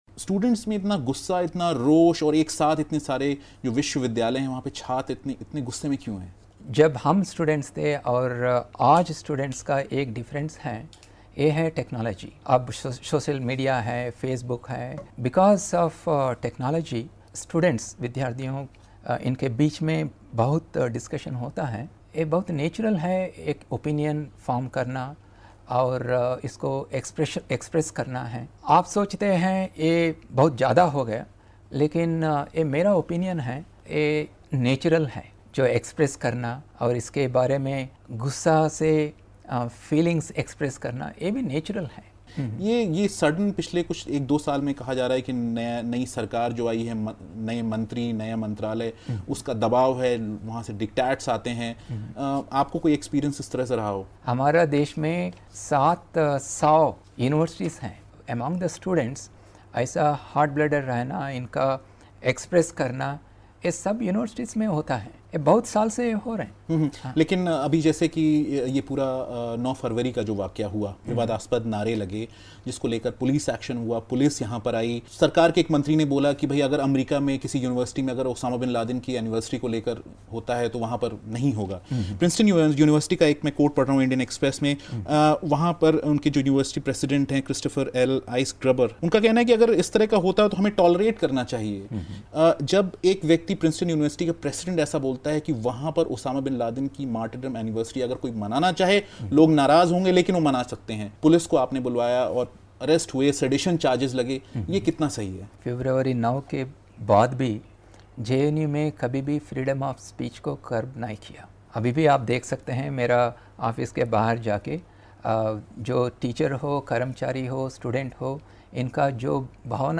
जेएनयू वीसी प्रोफ़ेसर एम जगदेश कुमार से बीबीसी की बातचीत.